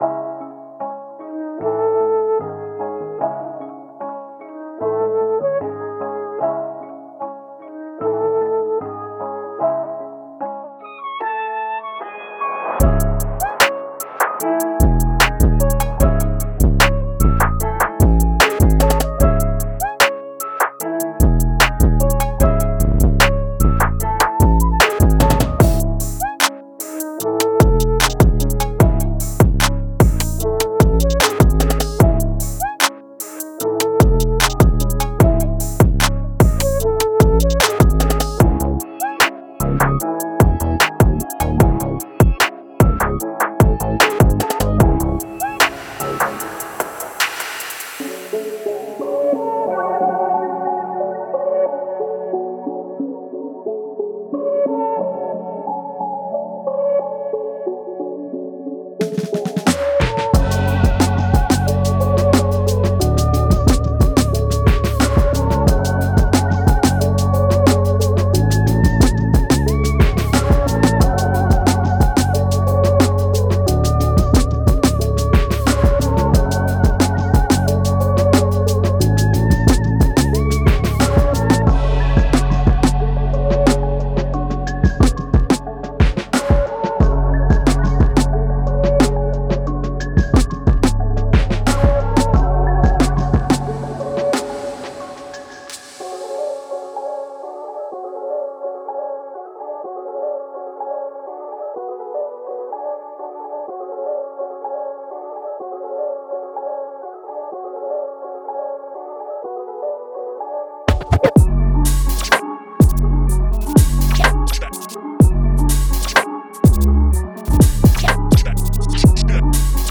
Plug巧妙地将Trap的超音波和优美的制作品质与HipHop音乐元素的更具情感色彩融合在一起。
-102个鼓点
-77个鼓循环
-128个音乐循环